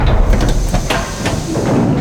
startdocking.ogg